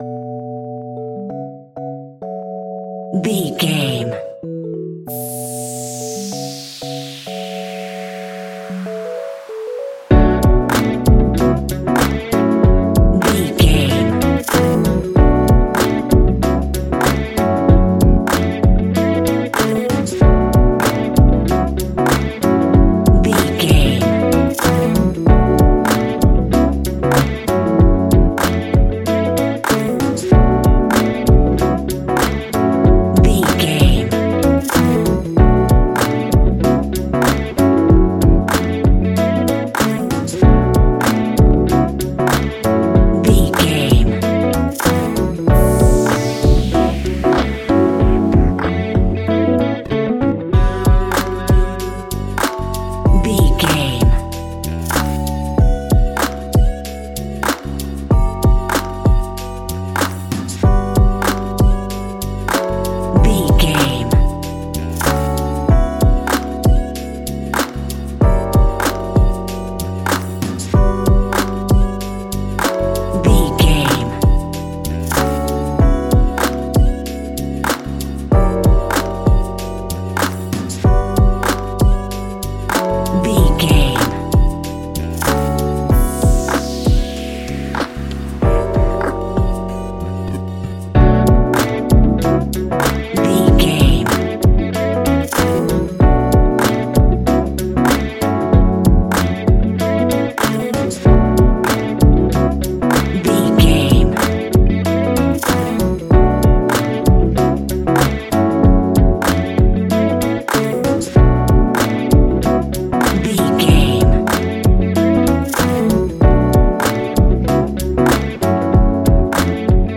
Ionian/Major
F♯
laid back
Lounge
sparse
new age
chilled electronica
ambient
atmospheric